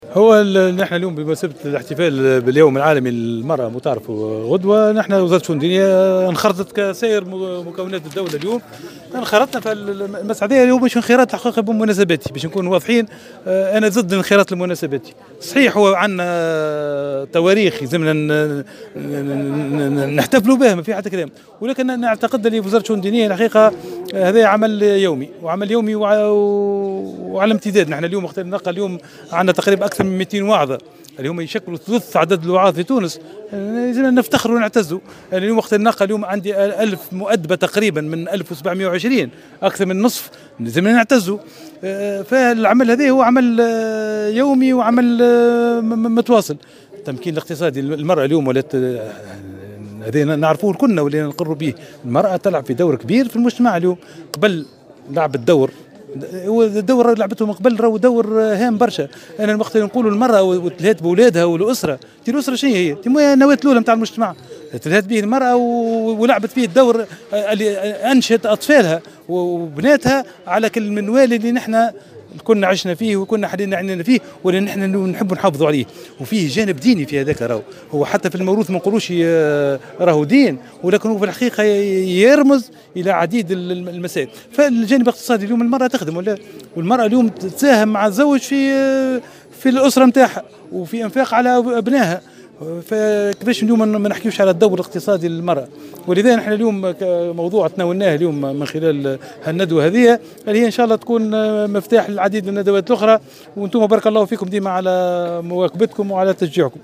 أكد وزير الشؤون الدينية أحمد عظوم في تصريح لمراسل الجوهرة "اف ام" اليوم الخميس 7 مارس 2019 أن وزارته انخرطت في الاحتفاء باليوم العالمي للمرأة شأنها شأن باقي الوزارات مضيفا أن هذا الانخراط ليس مناسباتيا بل هو عمل يومي متواصل.